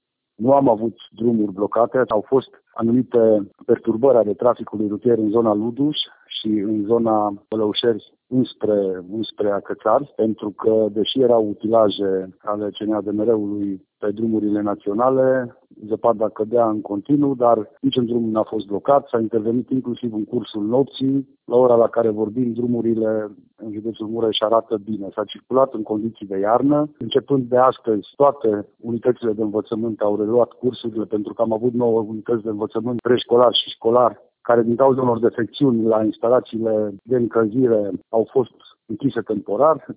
Prefectul județului Mureș, Lucian Goga, a arătat că acum circulația se desfășoară fără probleme deosebite: